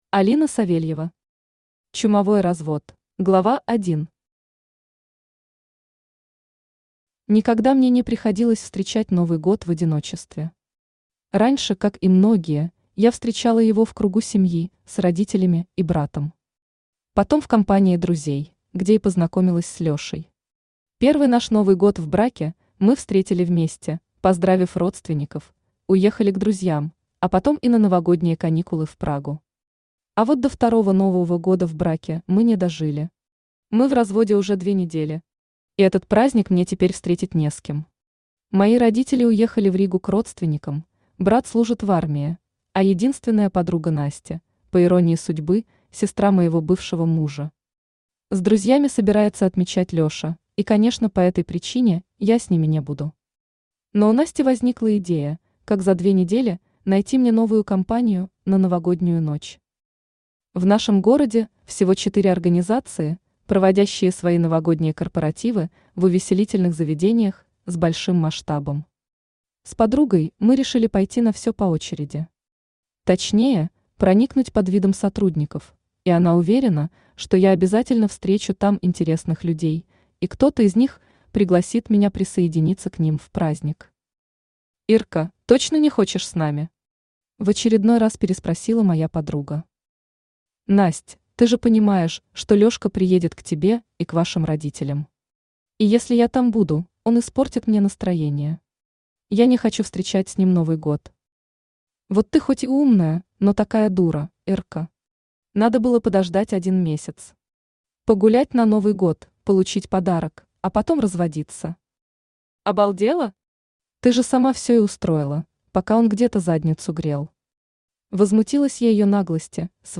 Aудиокнига Чумовой развод Автор Алина Савельева Читает аудиокнигу Авточтец ЛитРес.